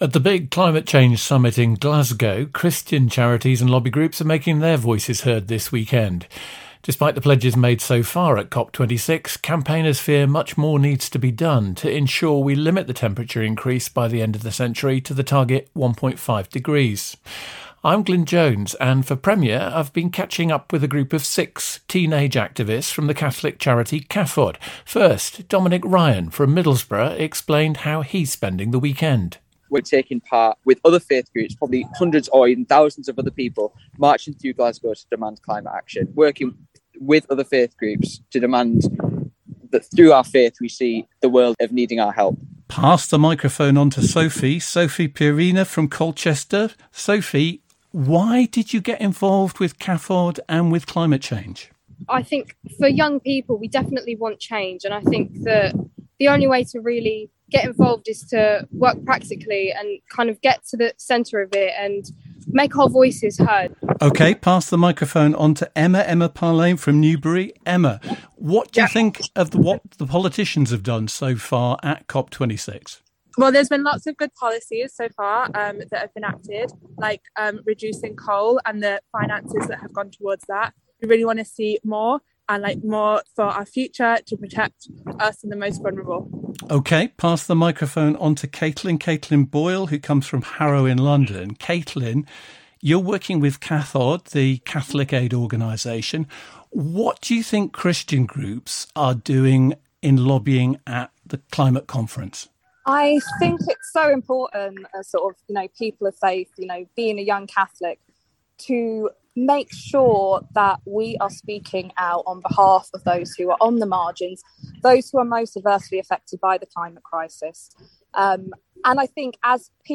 COP Climate Summit – interview with teenagers at COP26 in Glasgow (November 2021)